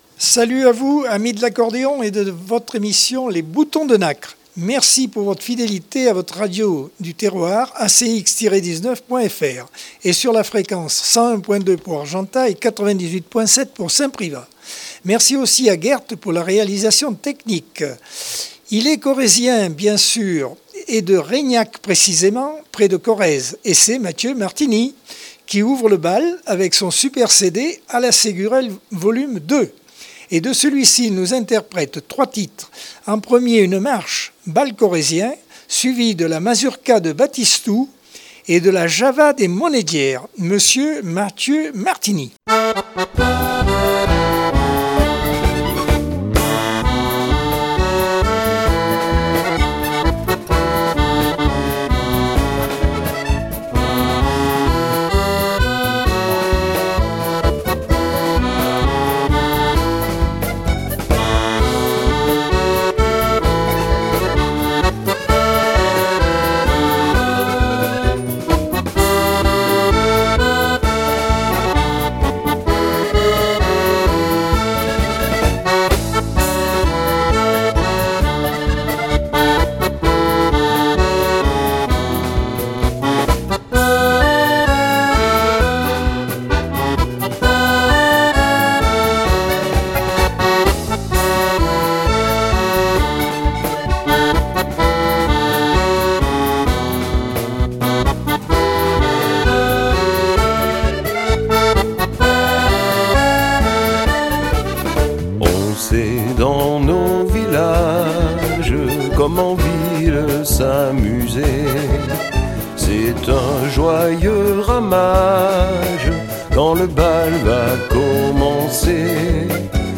Accordeon 2022 sem 42 bloc 1.